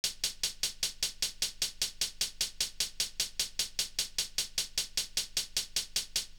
Closed Hats
Hihat2.wav